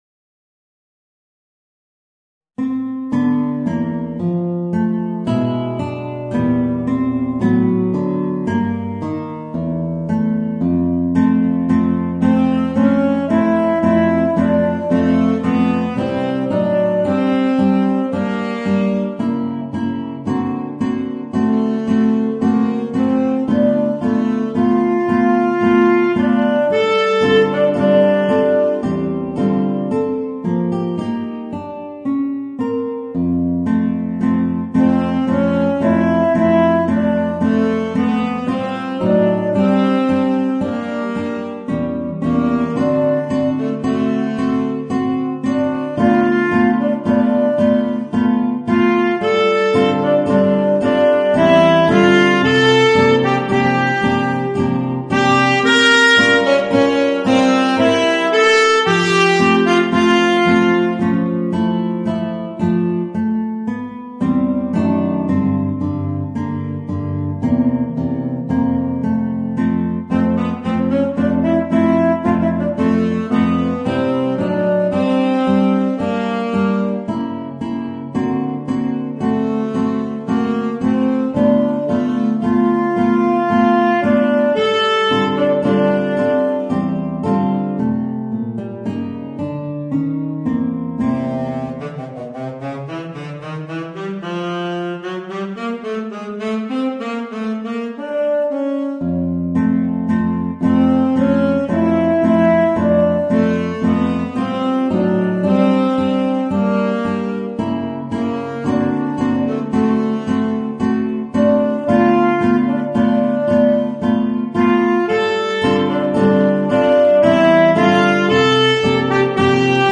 Voicing: Tenor Saxophone and Guitar